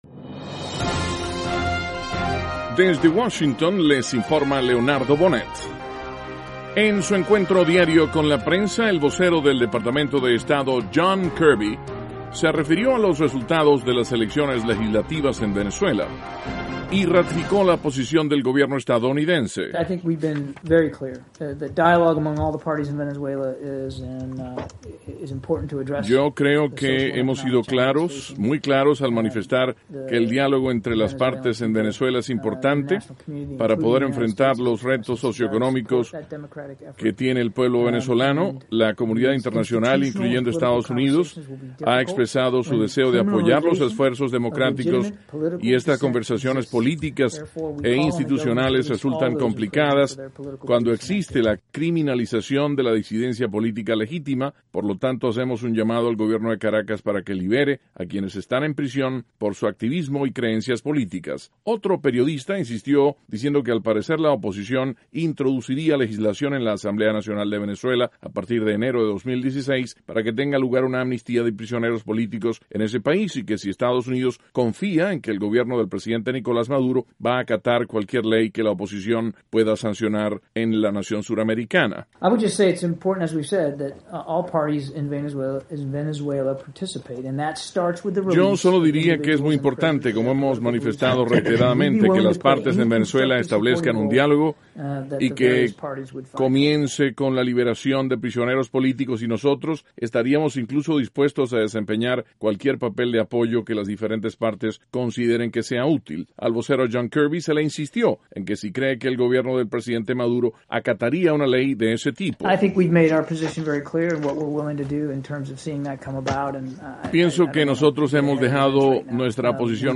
El vocero del Departamento de Estado, John Kirby, habla de lo que espera Estados Unidos que ocurra en Venezuela con la llegada de una nueva legislature, a partir del 5 de enero de 2016.